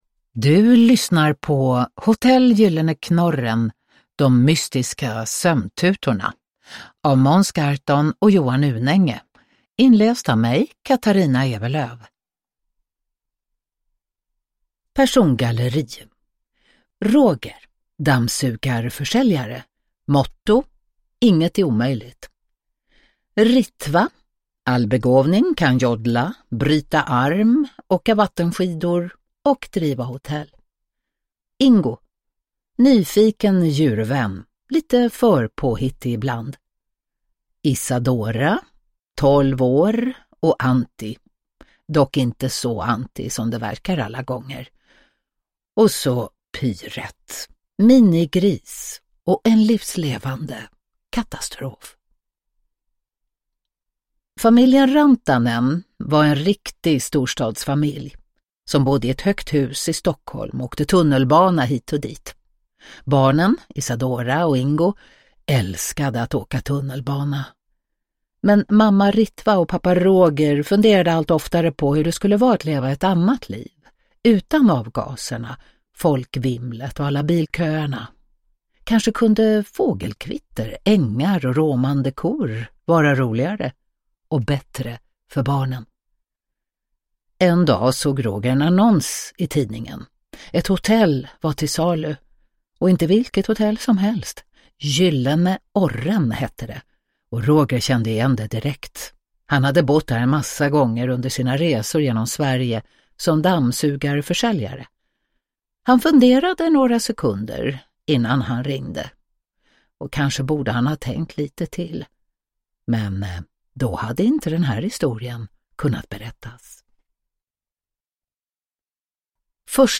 De mystiska sömntutorna – Ljudbok – Laddas ner
Uppläsare: Katarina Ewerlöf